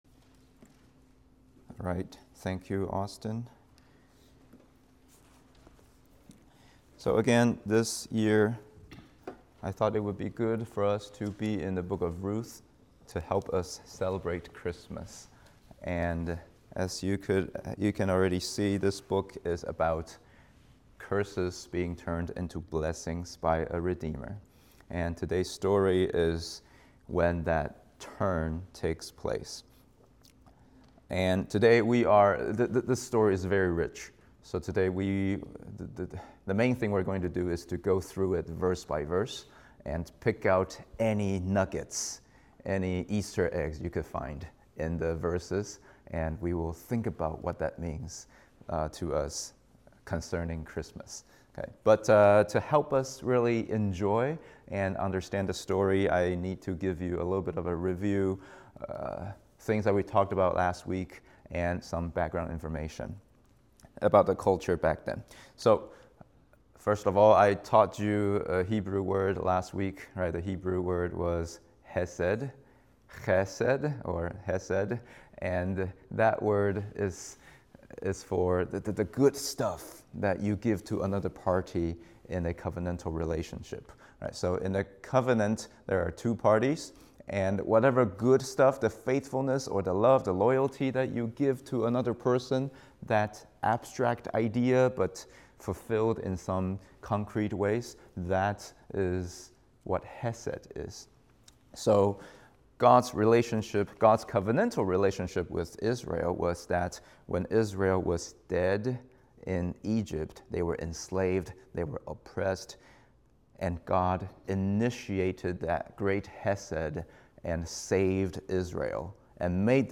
English Sermon